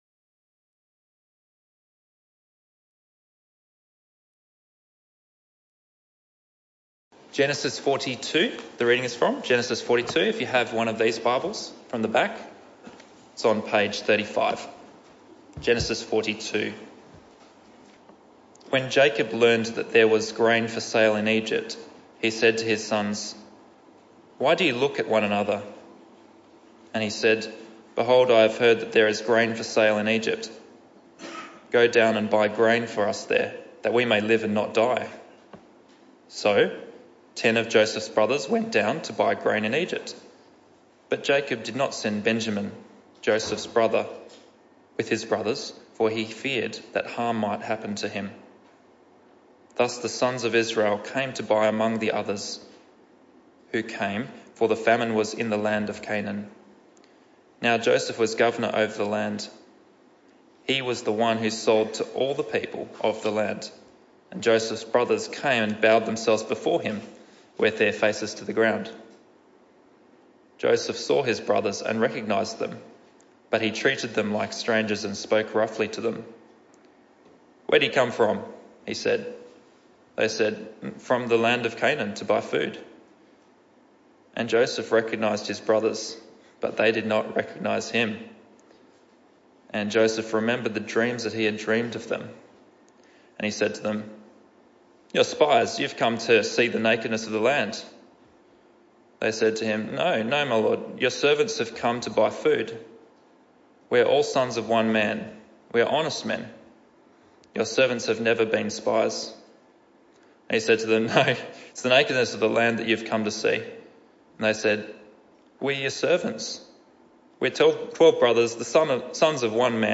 This talk was part of the AM Service series entitled God’s Blessing: To Abraham And Beyond Part 3.